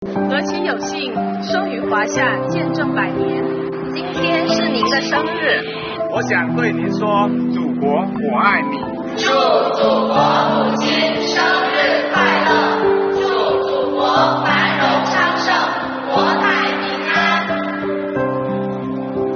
来宾市税务局青年干部用满怀真情的声音，表达对伟大祖国的无限热爱和美好祝福！